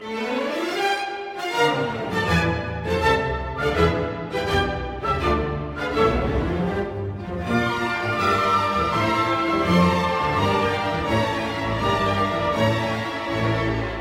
Lui succède immédiatement (mes. 56, env. 2'50'') une impétueuse Idée secondaire dont la physionomie rythmique contraste totalement avec les thèmes qui précèdent.